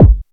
drum19.ogg